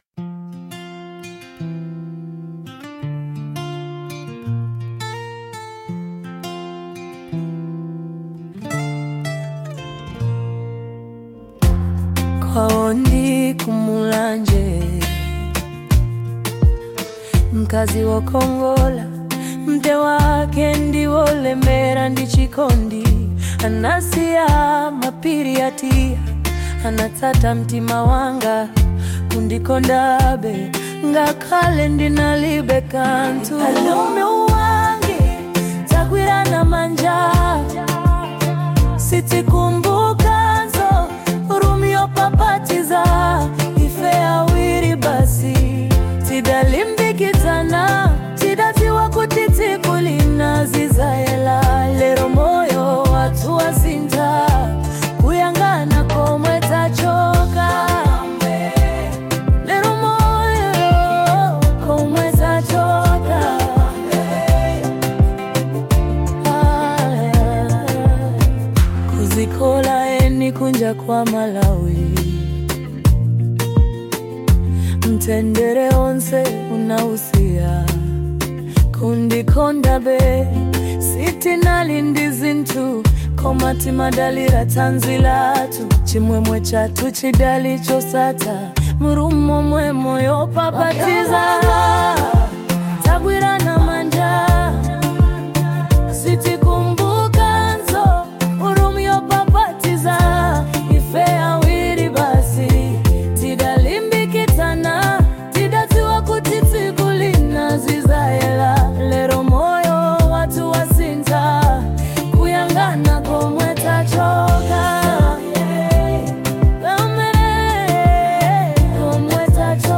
Genre : Afro Soul